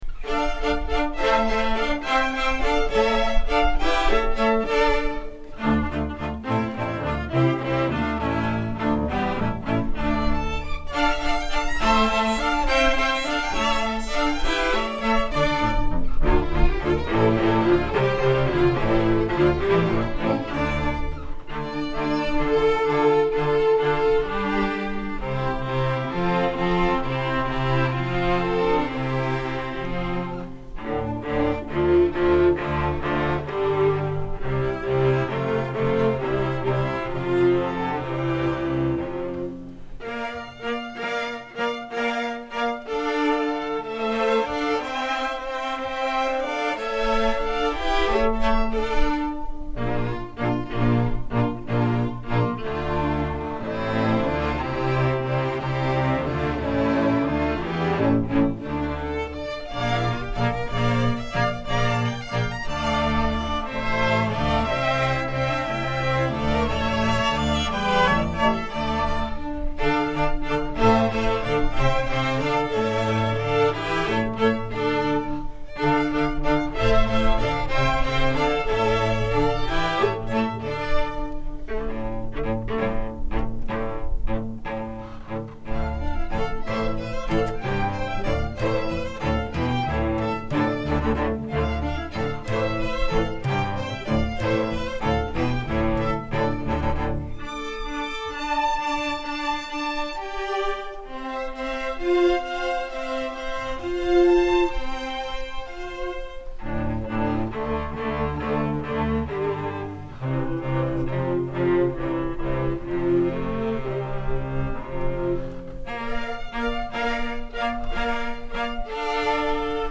“Din Dirin Din” για Ορχήστρα Εγχόρδων (live)